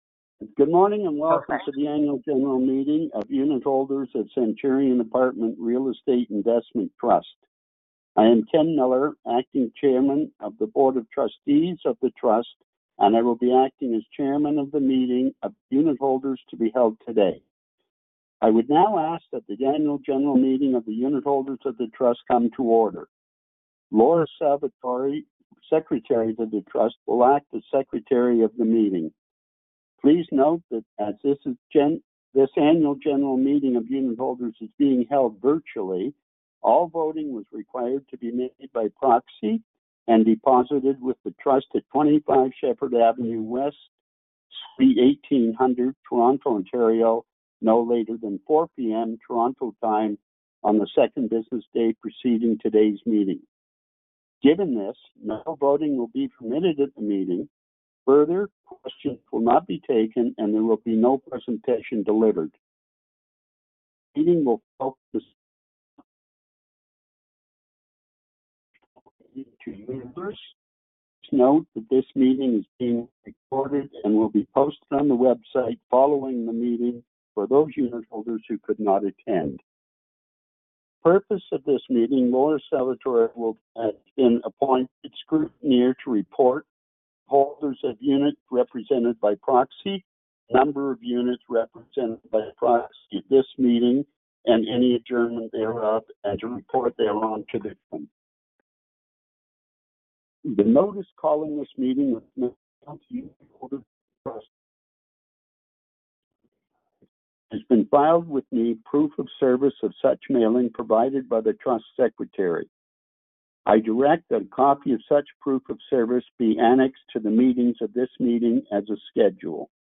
ANNUAL GENERAL MEETING
The Annual General Meeting of Unitholders of Centurion Apartment Real Estate Investment Trust (“REIT”) and the Annual General Meeting of Unitholders of Centurion Financial Trust (“CFIT”) were held via conference call on September 18, 2025.